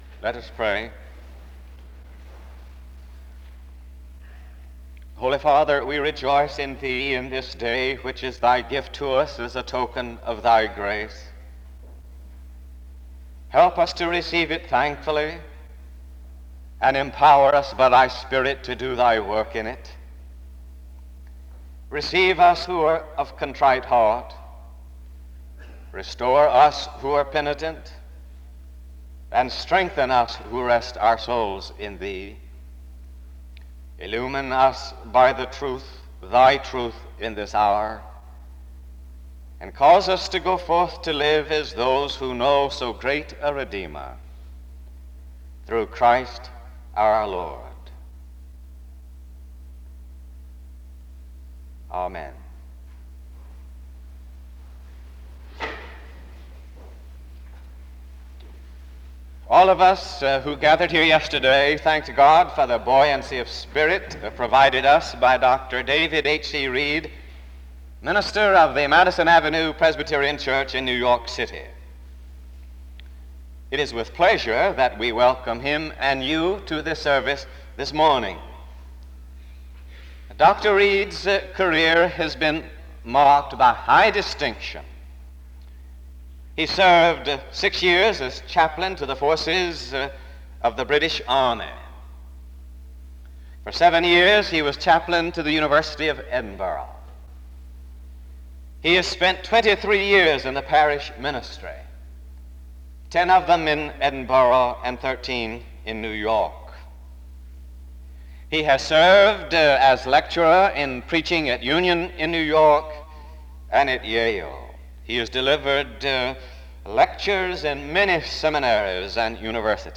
SEBTS Fall Lecture
SEBTS Chapel and Special Event Recordings